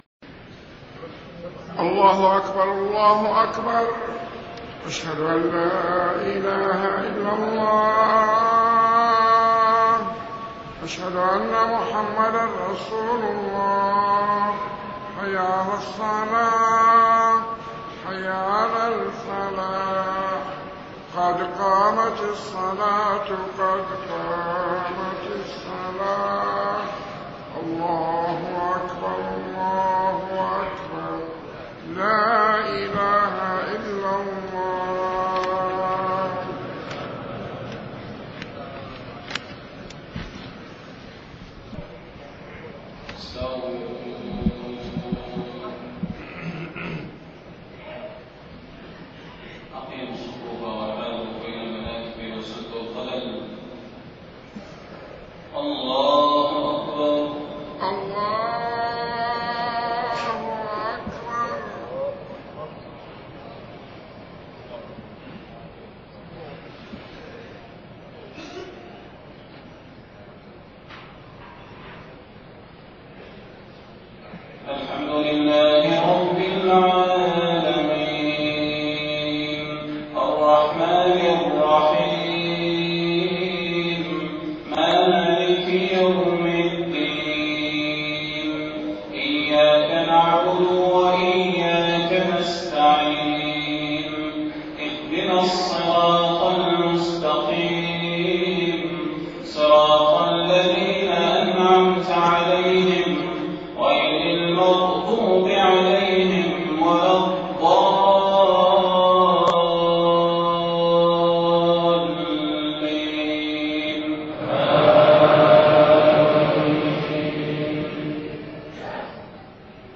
صلاة المغرب 22 محرم 1430هـ خواتيم سورة القمر41-55 > 1430 🕌 > الفروض - تلاوات الحرمين